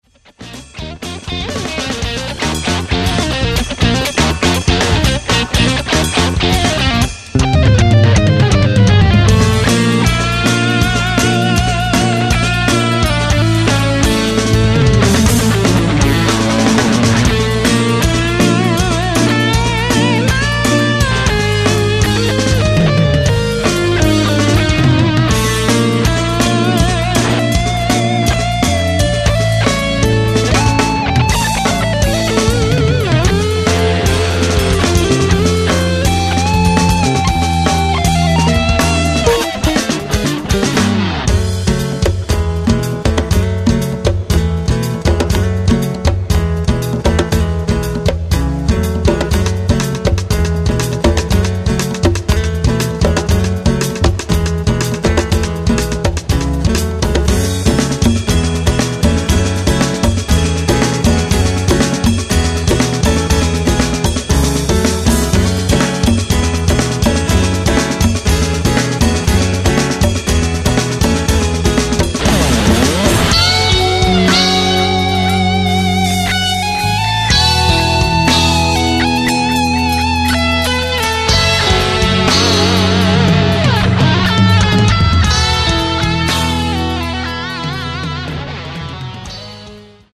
instrumentale gitaarmuziek